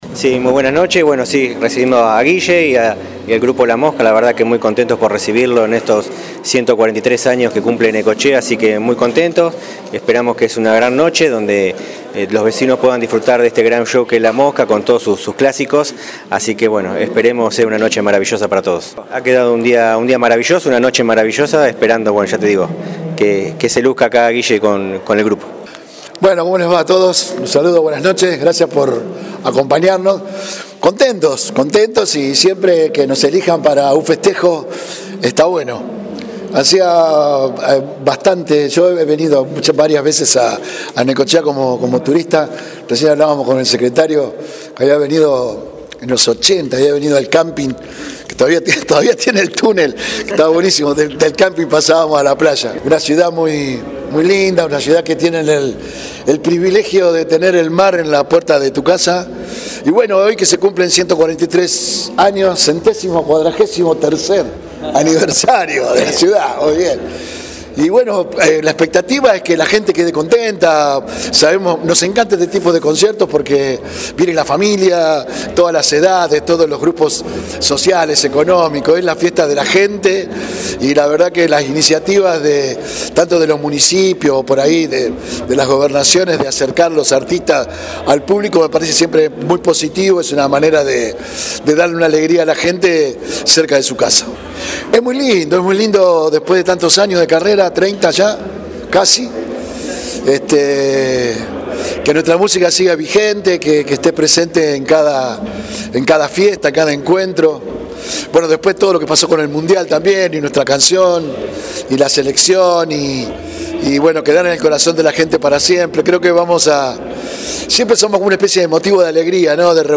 En su contacto con la prensa en el hall municipal, Novellis manifestó que “estamos contentos y siempre que nos elijan para un festejo está bueno, he venido muchas veces como turista a Necochea, en los ‘80  venía de camping, es una ciudad muy linda donde tienen el privilegio de tener el mar en la puerta de su casa y hoy que cumplen 143 años la expectativa es que la gente quede contenta”.